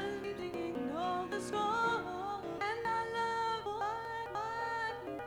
transferring music from a cassette
When I play it back after recording a song it sounds like I am playing a 33 rpm album in 45 speed.
The clip is too fast and has pieces missing, so it is a recording problem. The recording is too quiet, so will have surface noise when amplified to a reasonable level.